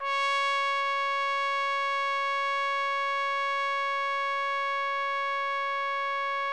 TRUMPET   20.wav